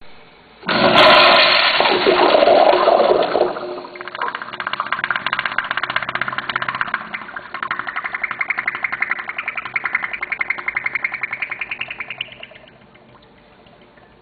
随机 " 小便池冲水室对准墙面，特写，和超特写
描述：小便池冲洗宽敞瞄准墙壁，特写镜头和supercloseup.flac
标签： 冲洗 小便器 宽敞 靠近
声道立体声